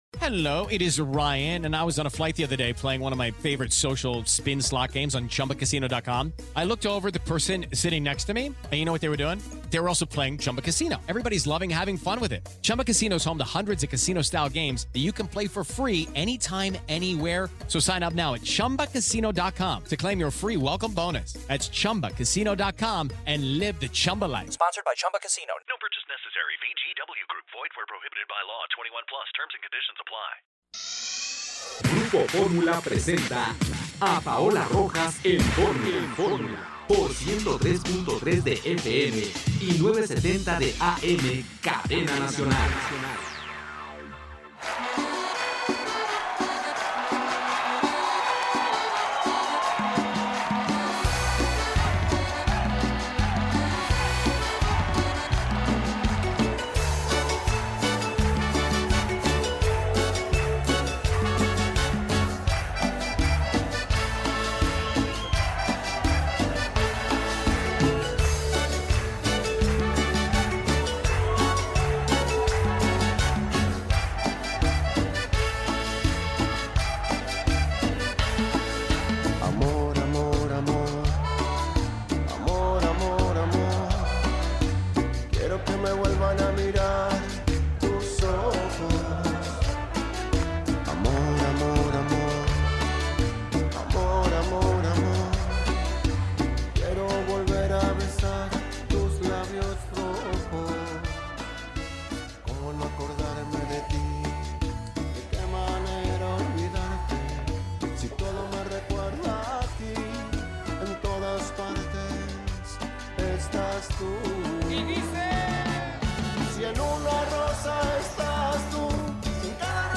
El lado objetivo de la información en voz de una mujer fresca, Paola Rojas en Grupo Fórmula.
… continue reading 85 episodios # Radioformula # Grupoformula # Radio Fórmula # Paola Rojas # Discusión de Noticias # Países Bajos Noticias # Noticias Diarias # Fórmula # Grupo Fórmula # Países Bajos Política